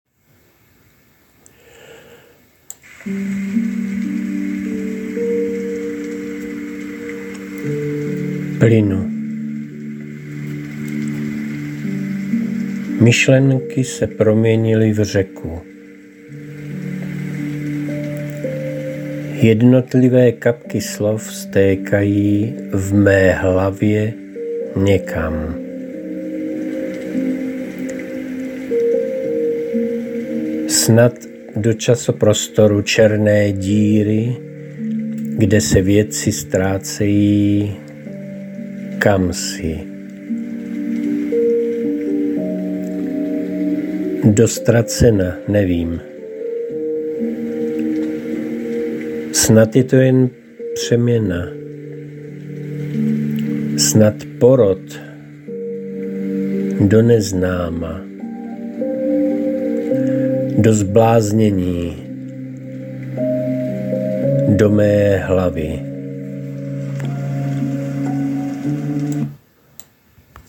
Působí velmi klidným dojmem.